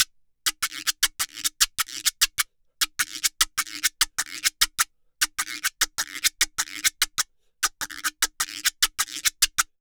Guiro_Salsa 100_2.wav